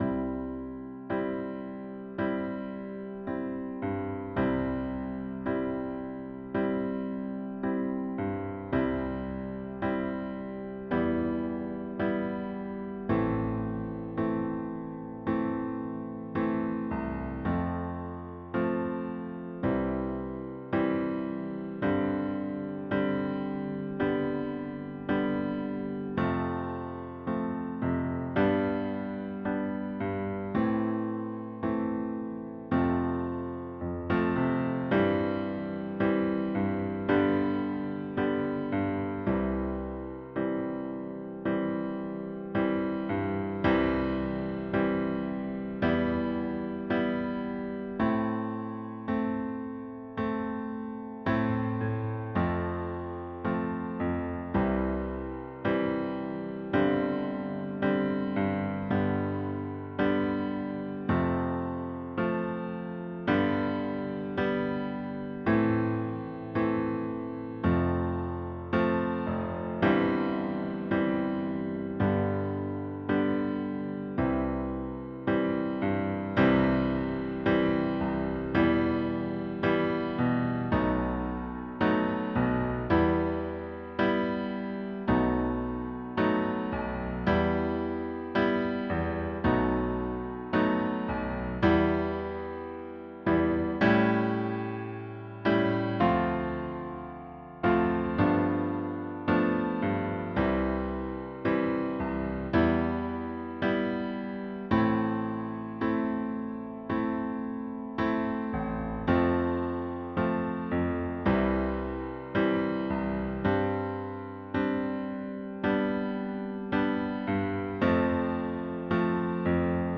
Piano track
Download Jam Track